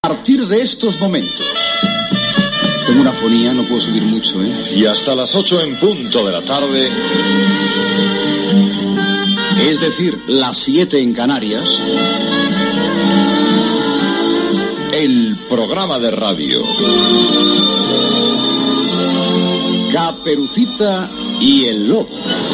Presentació inicial del programa
Entreteniment